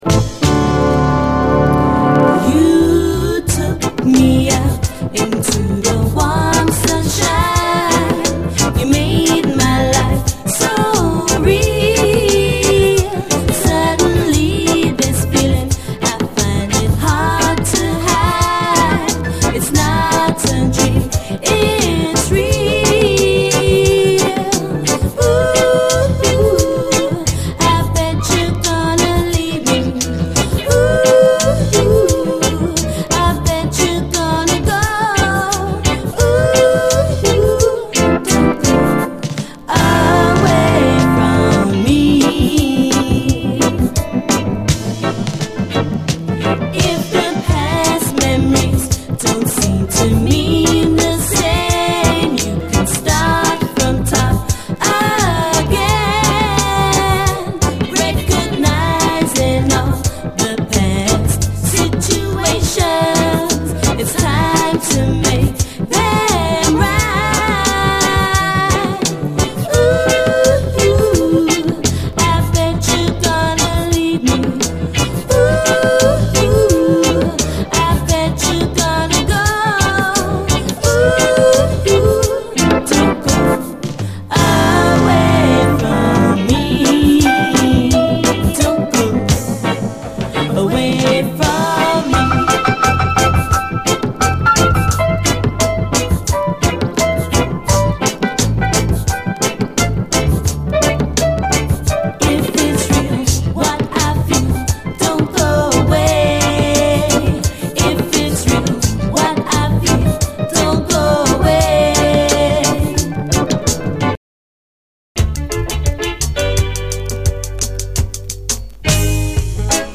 REGGAE
両面後半はダブ！